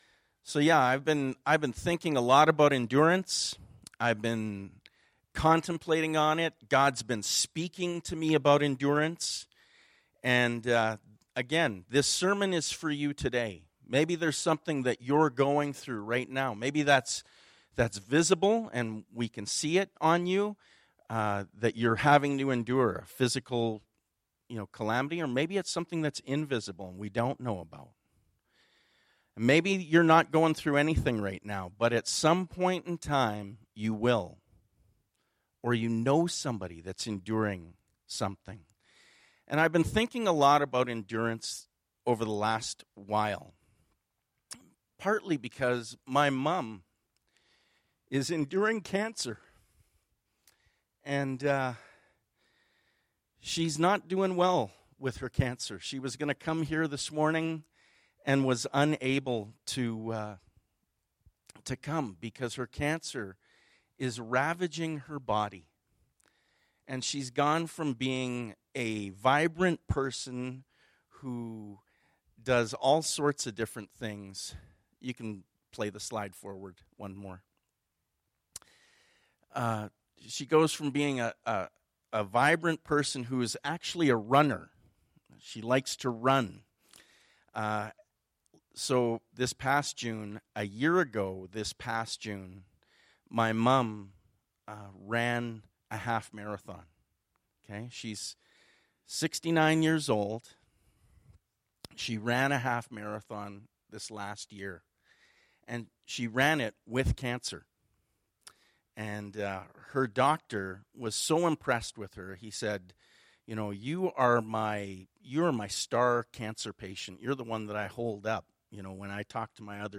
Bible Text: Hebrews 10:32-36; Hebrews 12:1-11 | Preacher: